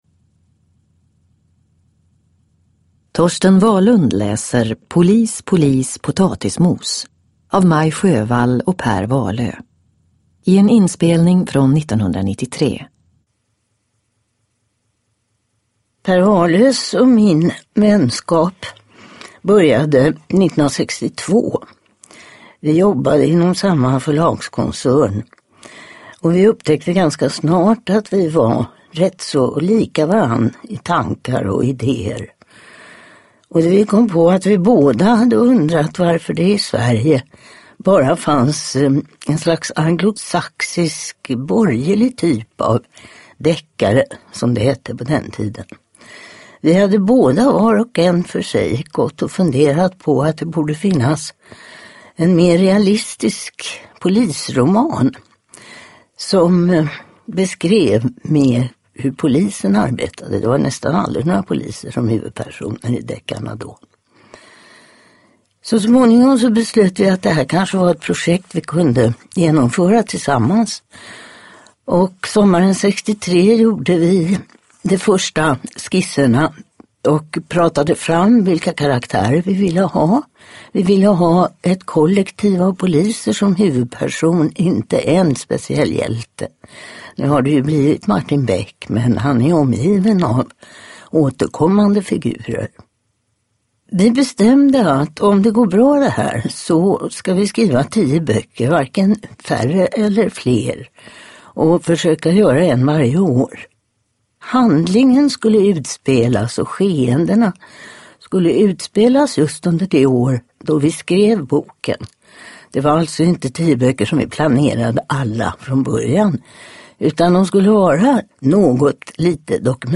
Polis, polis potatismos – Ljudbok
Uppläsare: Torsten Wahlund